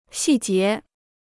细节 (xì jié): details; particulars.